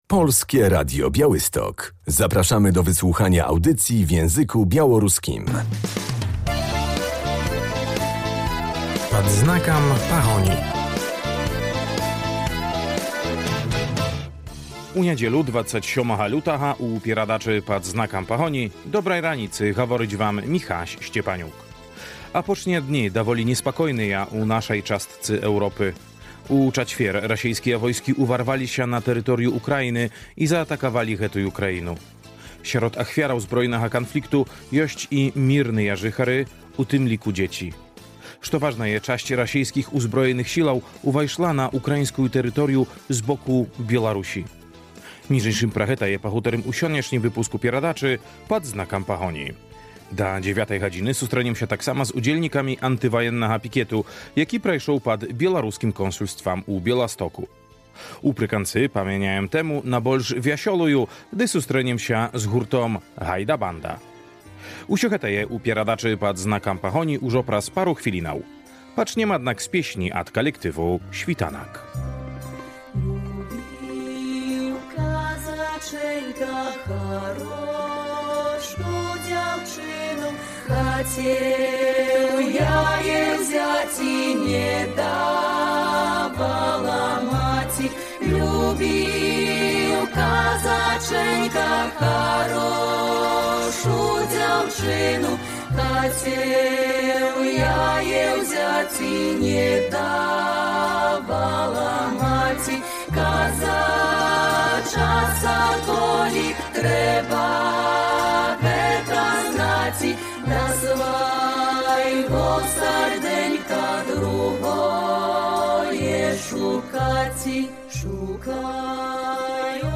"Sława Ukraini", "Żywie Biełaruś" - między innymi takie hasła można było usłyszeć w czwartek pod konsulatem Republiki Białoruś w Białymstoku.